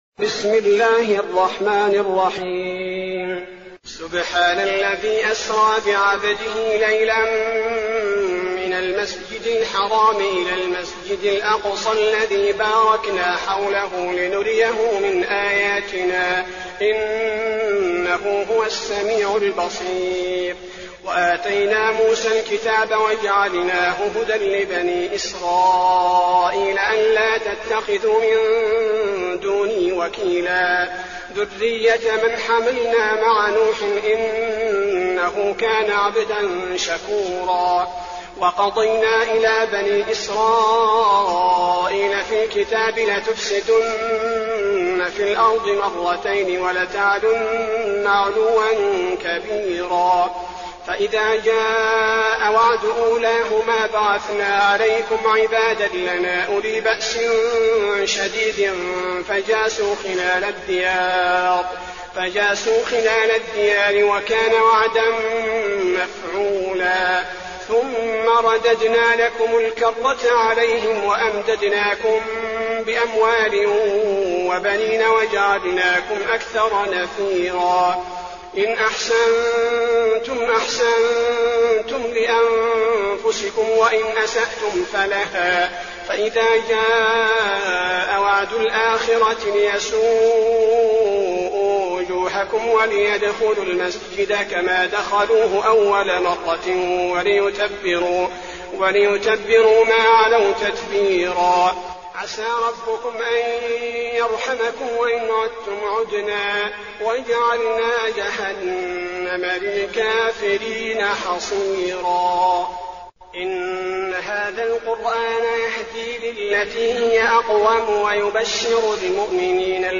المكان: المسجد النبوي الإسراء